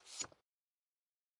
基础音效 " 菜单弹奏声
描述：我的手机上记录了由Flicking纸张创建的内容，并且没有添加任何效果，只进行了修剪。
Tag: 游戏 菜单 弗里克